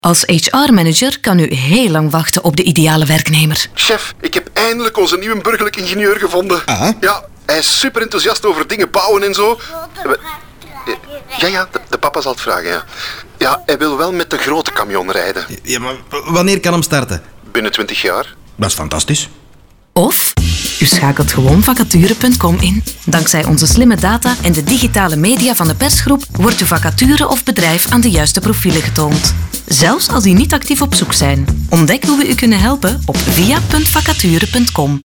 Commerciale, Naturelle, Enjouée, Fiable, Chaude
Vidéo explicative